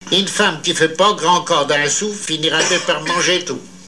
Mémoires et Patrimoines vivants - RaddO est une base de données d'archives iconographiques et sonores.
Genre dicton
émission La fin de la Rabinaïe sur Alouette